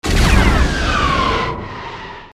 fire_missile.mp3